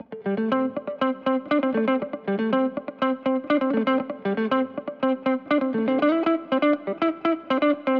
31 Pickin Guitar PT1+2.wav